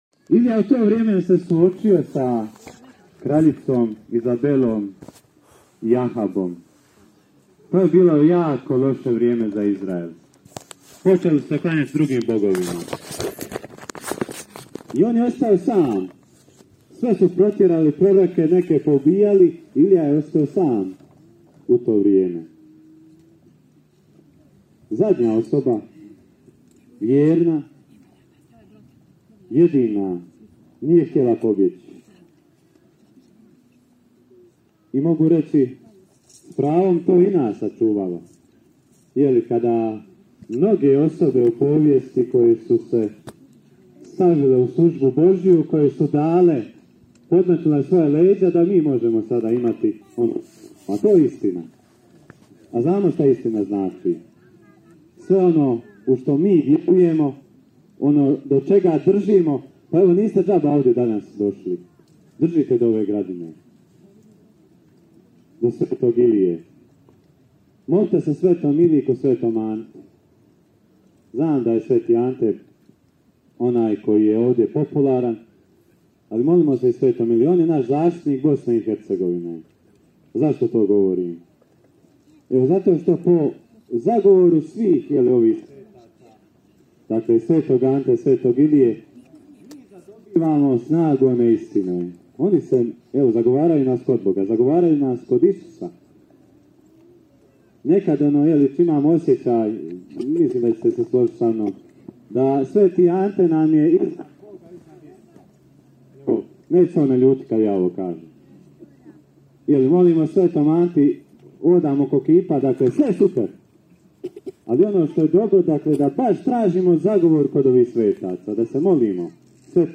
Svečano misno slavlje u čast svetom Iliji Proroku, danas je služeno, po tradiciji, na Gradini, iznad duvanjskog sela Stipanića, ispred križa i kipa svetog Ilije.